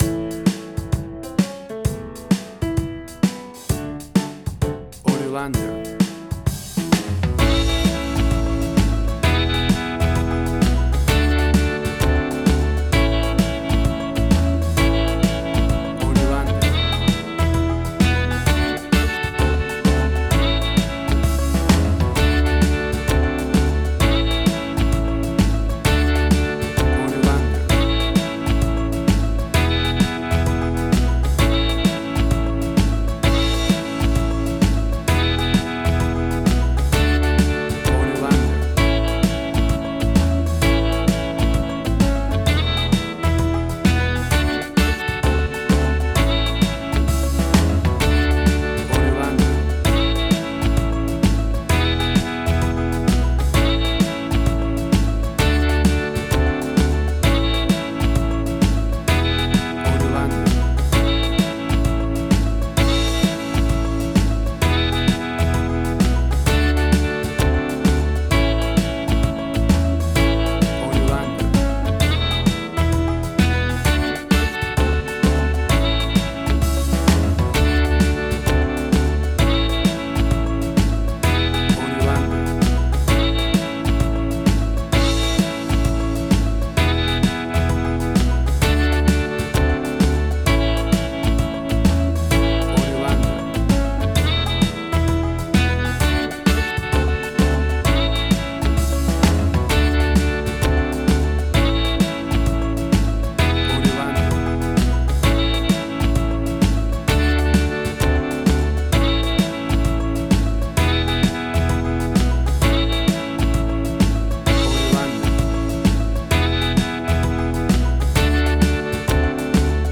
WAV Sample Rate: 16-Bit stereo, 44.1 kHz
Tempo (BPM): 130